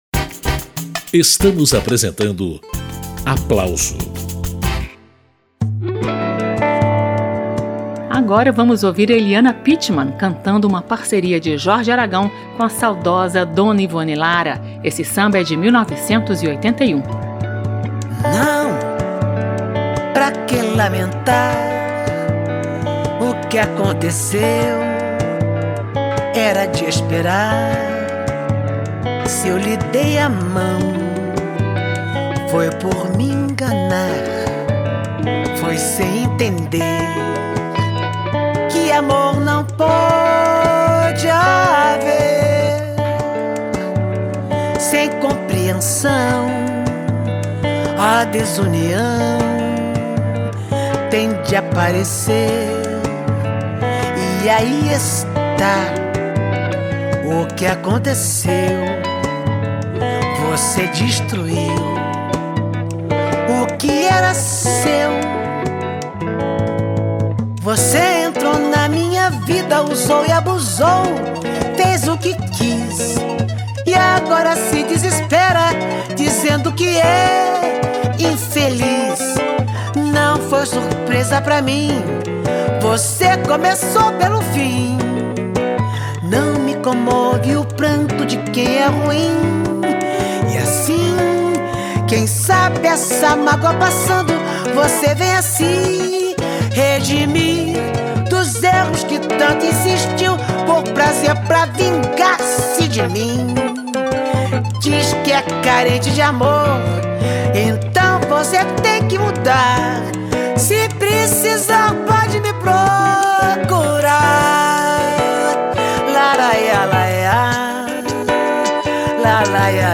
A cantora Eliana Pittman, prestes a completar 80 anos de vida, está a todo vapor com o lançamento do álbum Nem Lágrima Nem Dor, onde interpreta sucessos de Jorge Aragão. Ela também está às voltas com a finalização de uma biografia que promete não esconder nenhum detalhe da longa trajetória. Pittman conta as novidades, nesta edição do programa Aplauso.